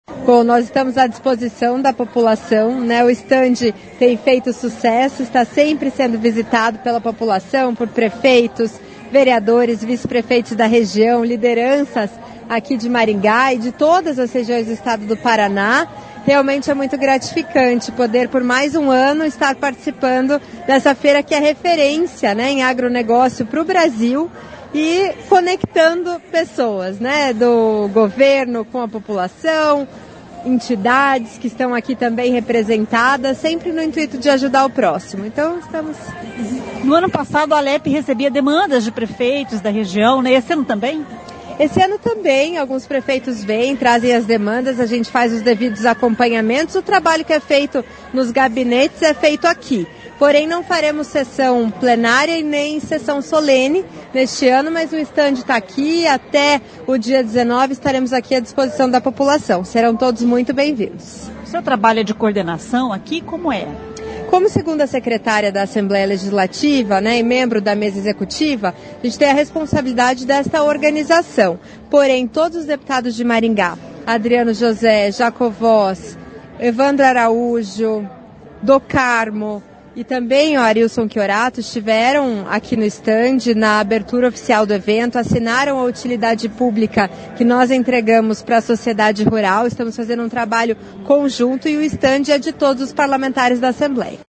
A deputada estadual Maria Victoria (PP) fala sobre a presença da Assembleia Legislativa do Paraná (Alep) na Expoingá 2024. O estande da Alep está recebendo visitantes e prefeitos da região.